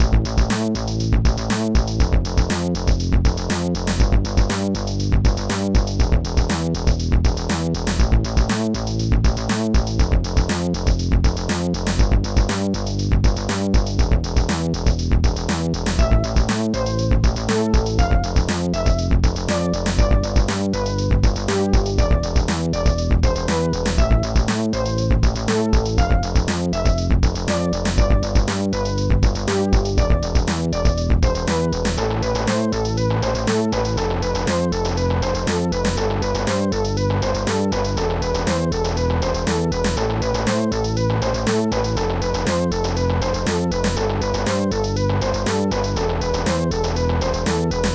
SoundTracker Module
Instruments bassdrum1 popsnare2 claps1 hihat2 magic funbass
DRUM03.mp3